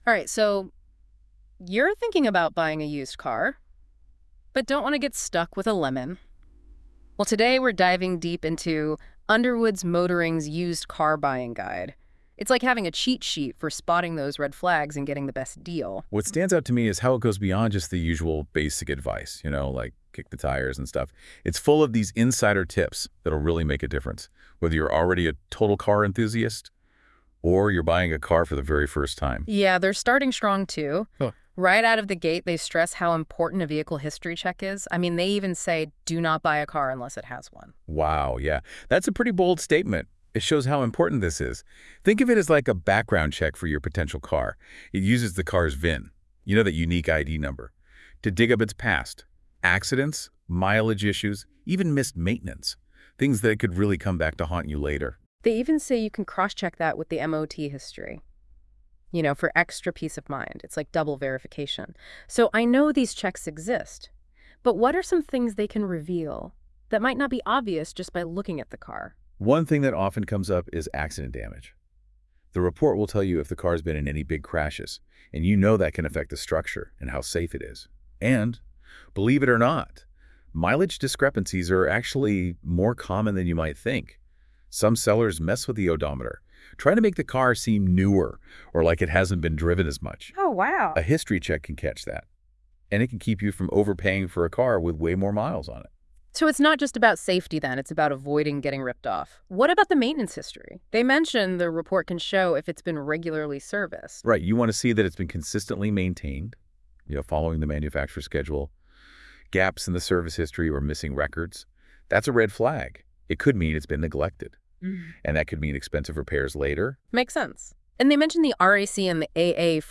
If you would prefer to hear this news blog generated as a conversational podcast by the astonishing powers of AI click here.